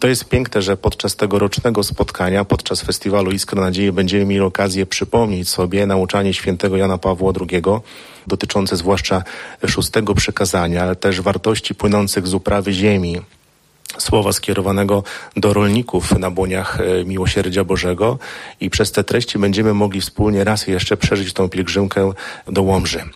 Uczestnicy dzisiejszej (13.09.) konferencji dotyczącej festiwalu 'Iskra Nadziei. Podlaskie w hołdzie Janowi Pawłowi II’ wspominali papieża Polaka.